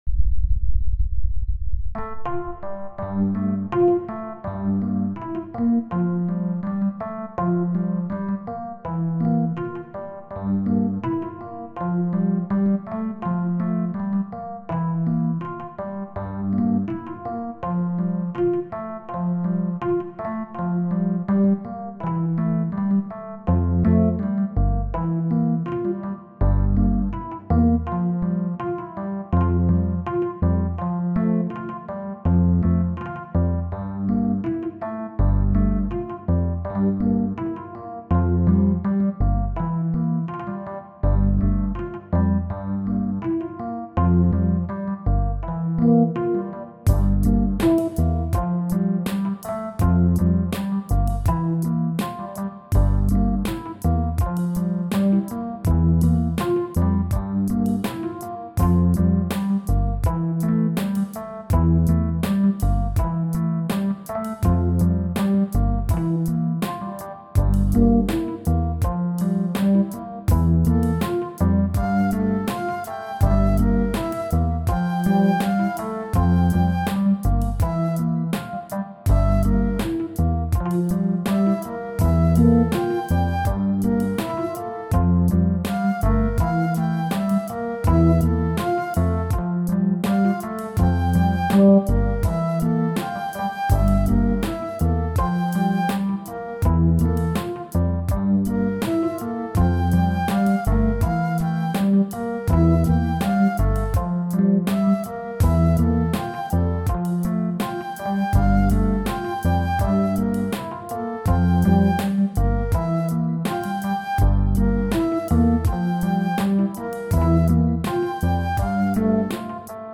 musique,  musique concrète,ambiance, fractale, son, bruit, samples, mp3, streaming
Brumenn est un duo piano électrique/flute alors que nijal est un morceau ambient/New age.
Henon ( 1.6 - 0.3) Sol (G) Dorien 82